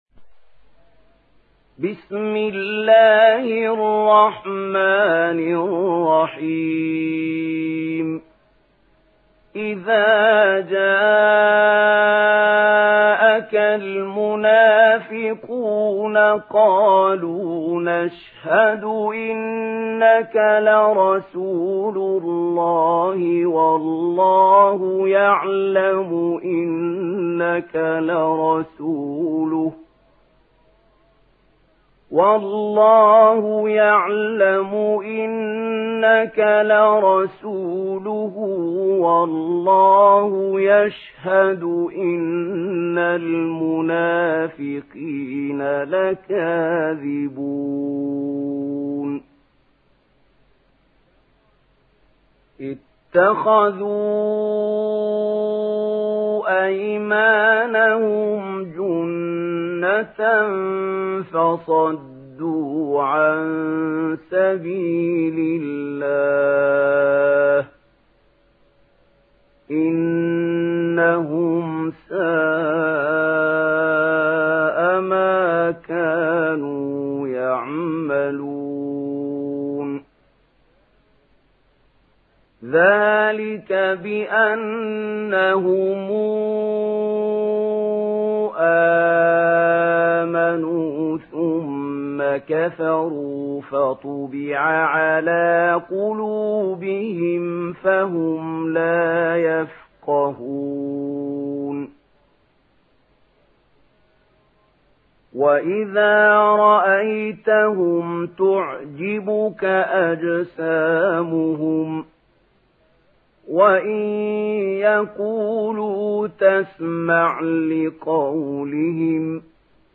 تحميل سورة المنافقون mp3 بصوت محمود خليل الحصري برواية ورش عن نافع, تحميل استماع القرآن الكريم على الجوال mp3 كاملا بروابط مباشرة وسريعة